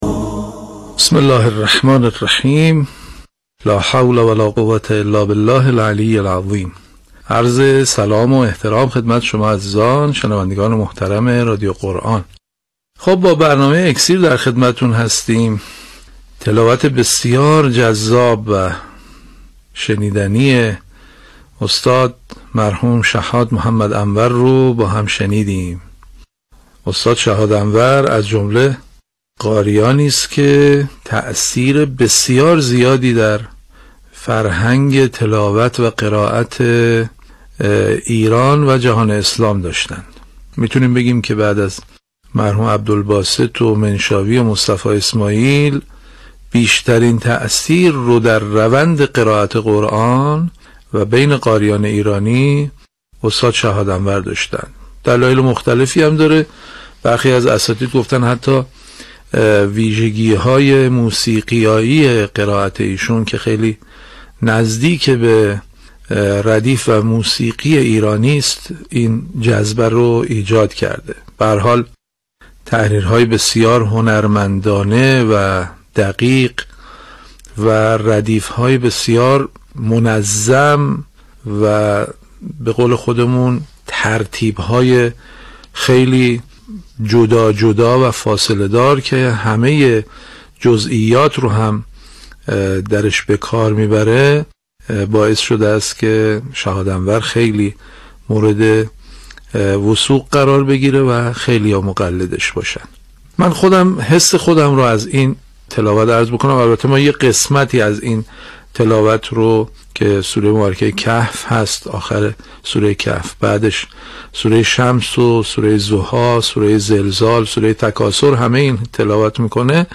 تحریرهای بسیار هنرمندانه، دقیق و ردیف‌های بسیار منظم و ترتیب‌های مجزا و فاصله‌دار که همه جزئیات را در آن به‌کار می‌برد، باعث شده است که شحات انور، مورد وثوق قرار گیرد و خیلی‌ها مقلد وی شوند.
یادآور می‌شود، این تحلیل در برنامه «اکسیر» به تاریخ 28 تیرماه 1397 ساعت ۱۶:۳۰ تا ۱۸ از شبکه رادیویی قرآن پخش شد.